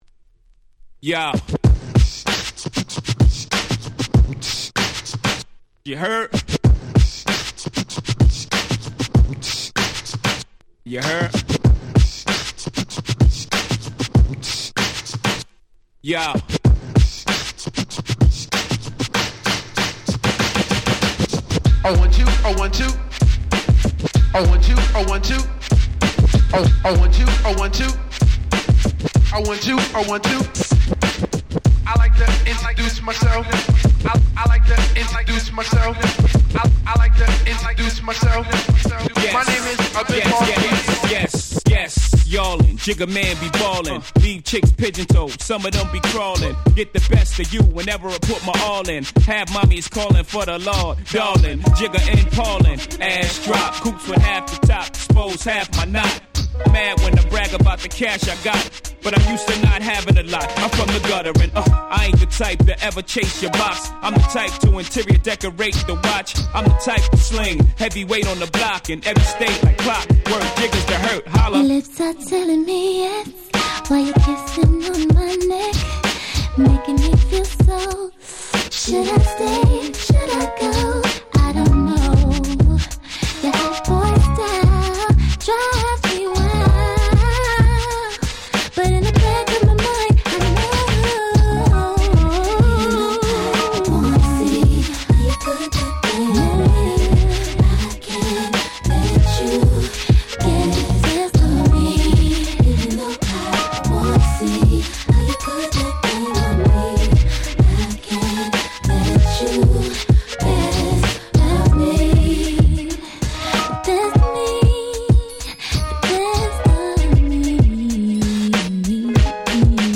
【Media】Vinyl 12'' Single
【Condition】C (スリキズ多めですがDJ Play可。チリノイズ出ます。)